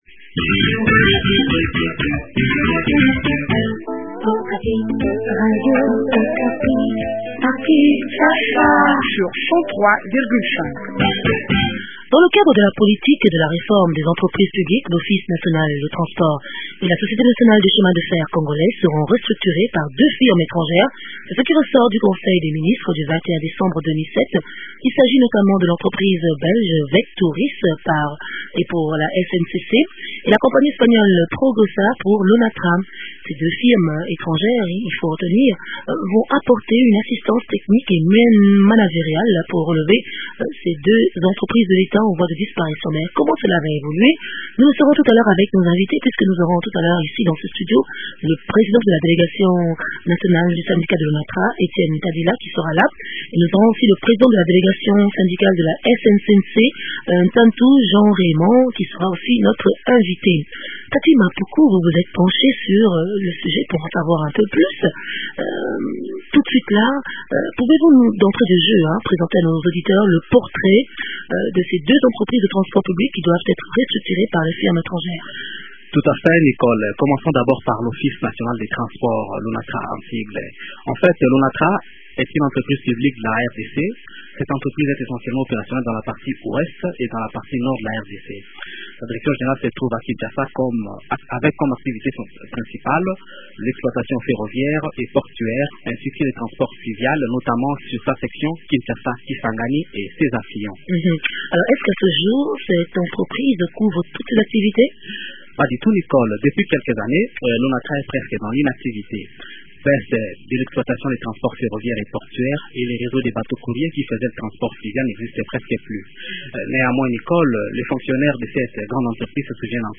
entretient